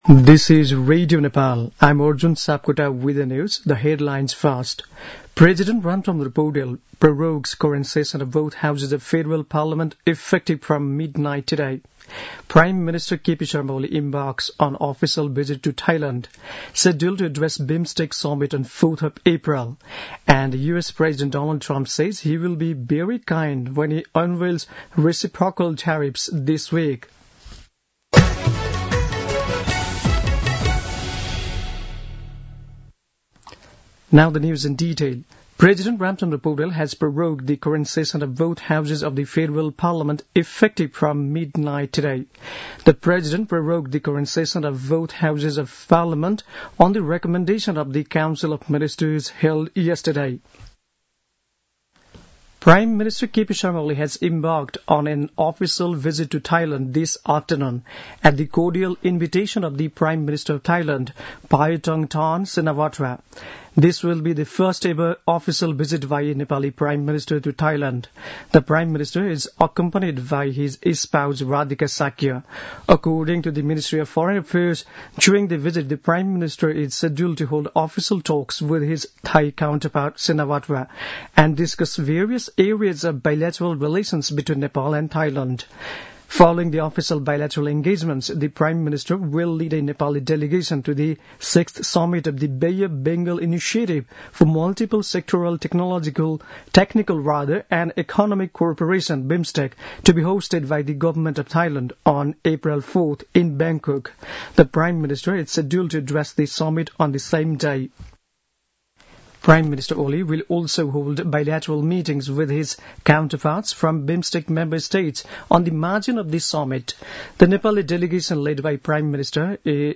दिउँसो २ बजेको अङ्ग्रेजी समाचार : १९ चैत , २०८१